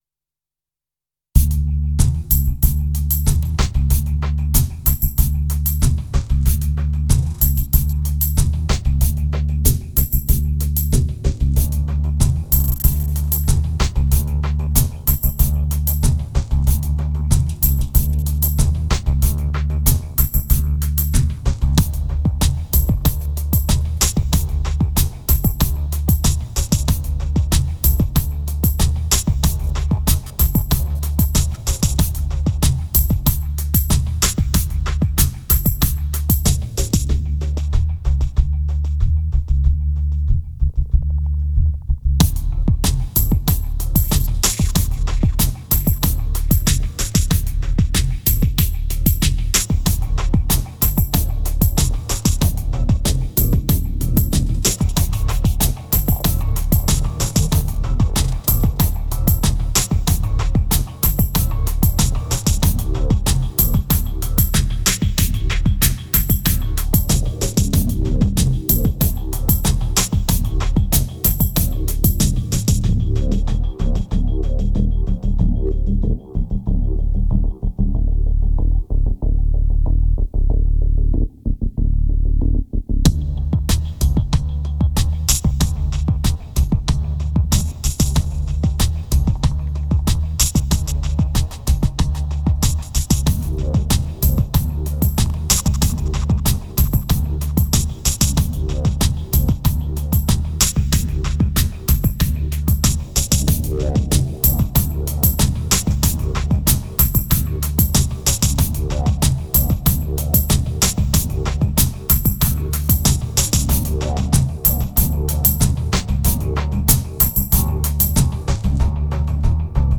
2273📈 - 3%🤔 - 94BPM🔊 - 2010-03-10📅 - -155🌟